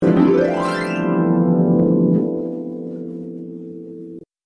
SONS ET LOOPS DE HARPES
Harpe 41